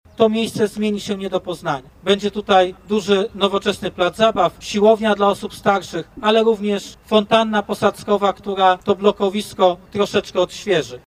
Mówił o tym prezydent Stalowej Woli Lucjusz Nadbereżny: